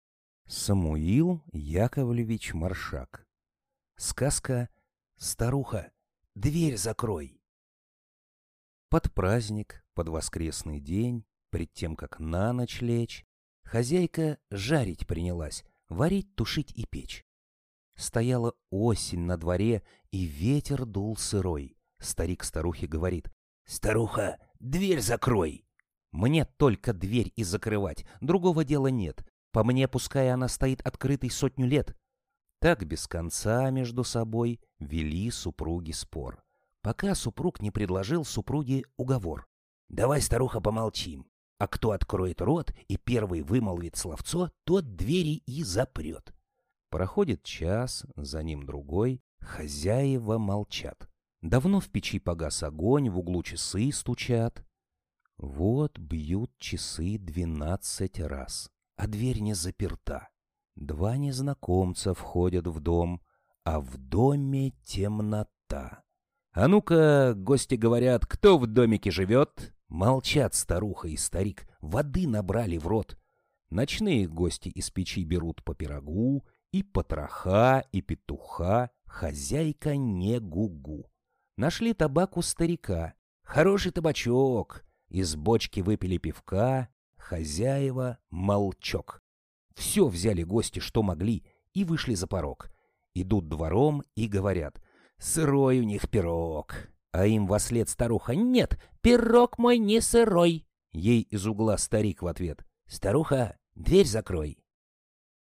Аудиосказка «Старуха, дверь закрой!»